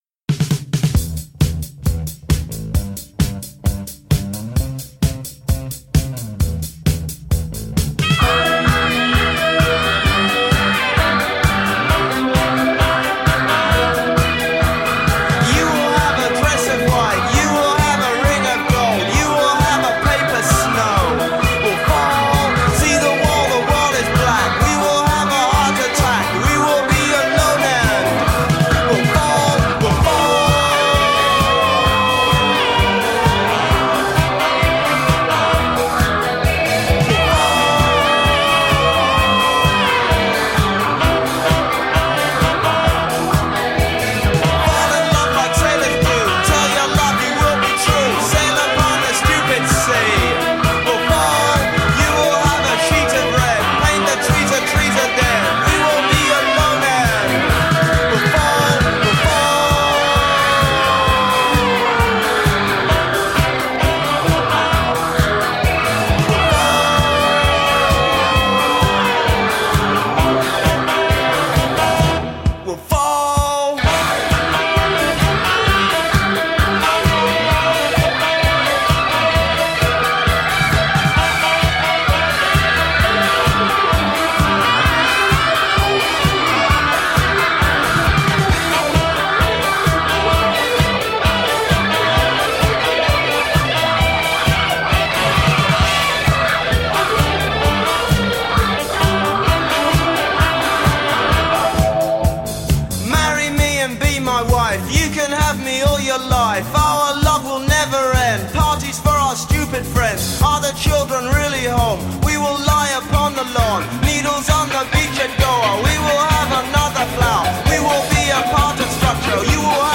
New Wave/New Psych.
bass guitar
saxophone
from Art Rock to Hard Rock, with stops in between.